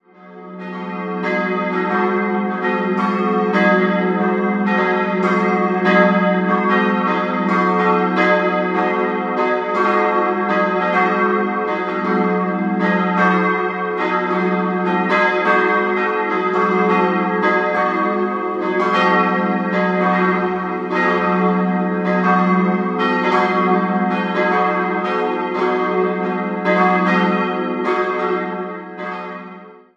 Der Turm erhielt seine heutige Form im Jahr 1776. Das neubarocke Oktogon kam 1914 zur Vergrößerung des Kirchenraumes hinzu. 3-stimmiges Geläut: dis'-fis'-a' Die Glocken wurden 1922 vom Bochumer Verein für Gussstahlfabrikation gegossen.